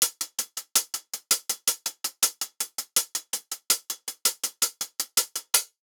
MZ HL [Dilla - 163BPM].wav